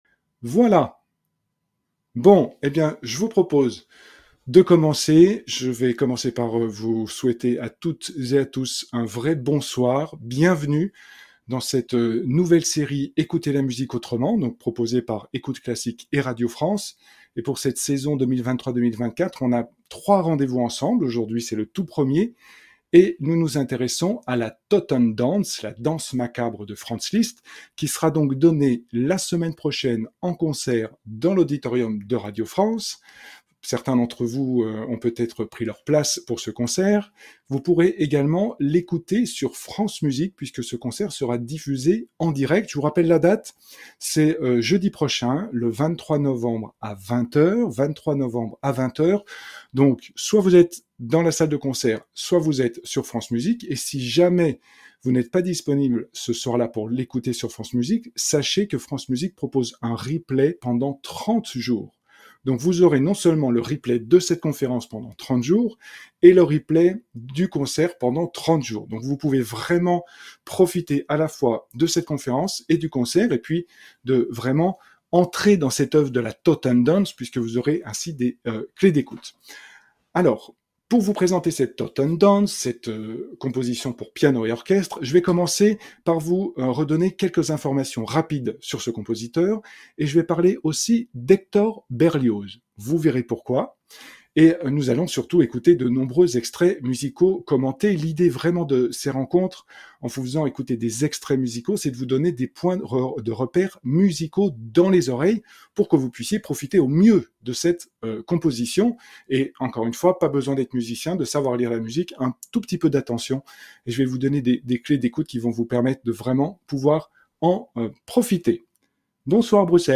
Conférence Liszt Radio-France - ECOUTE CLASSIQUE